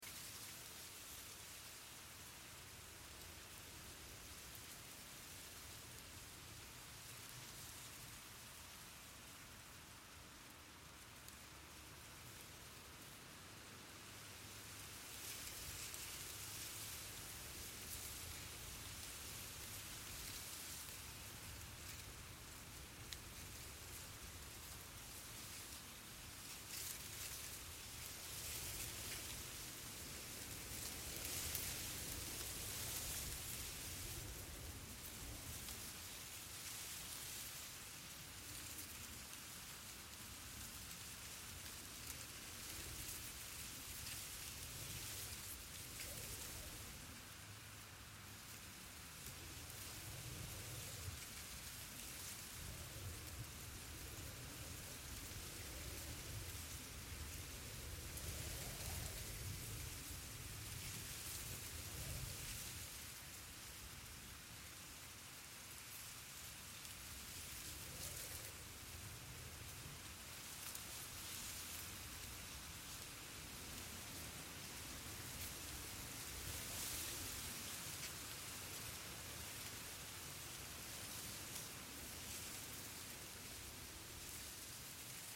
Звуки травы
Шелест высокой травы в поле – рожь или пшеница